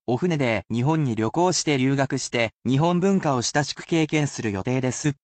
[basic polite speech]